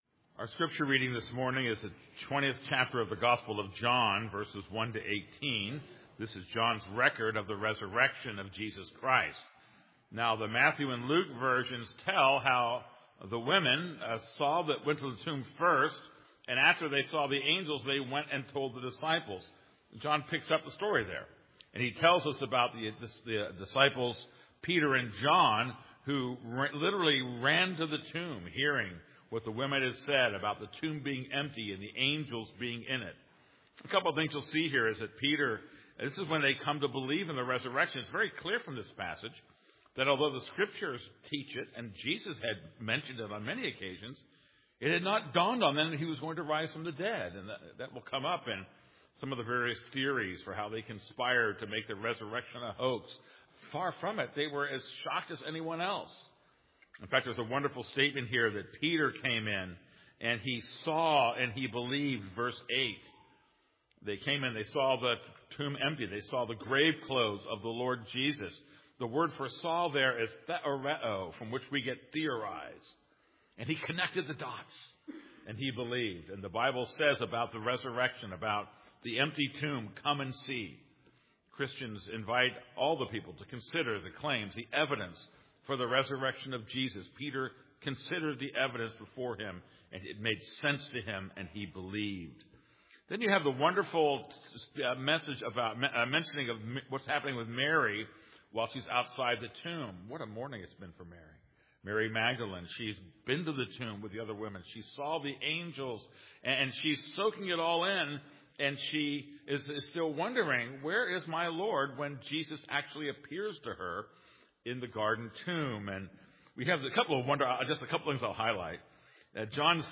This is a sermon on John 20:1-18.